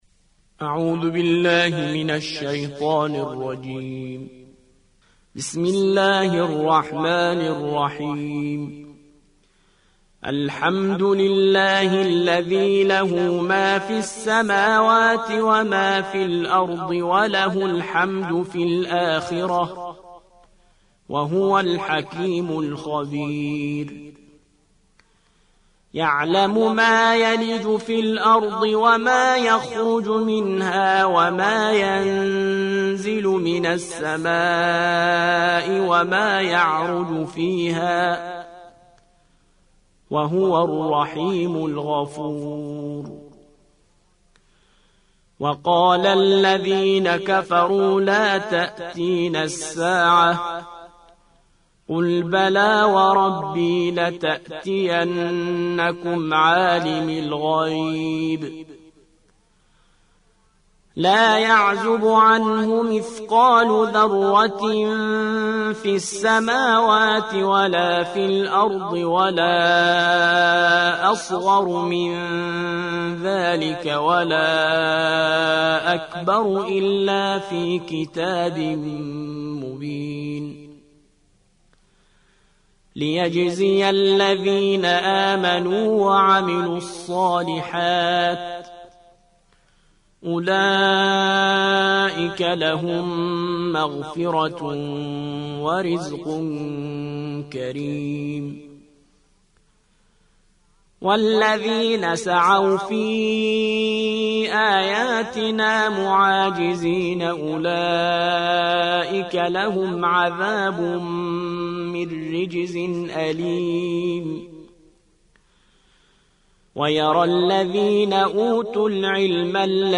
34. سورة سبأ / القارئ